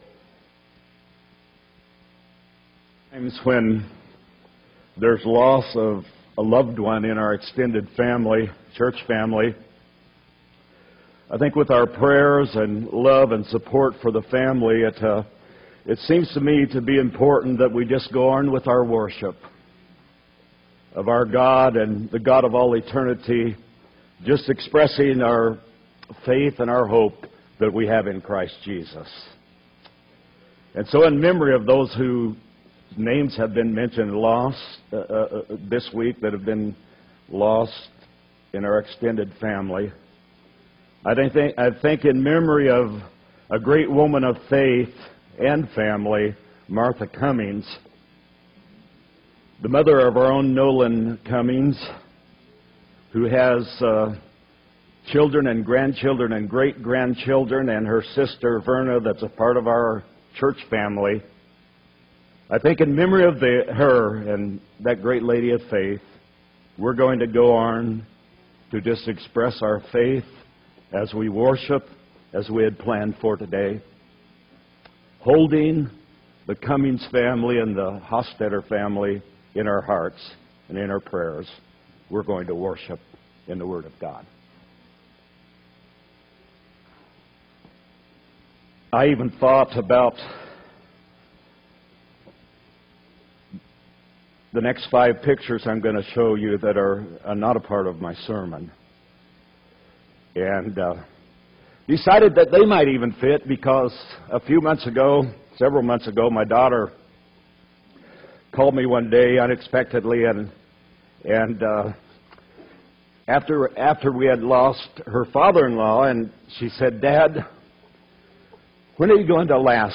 8-11-12 sermon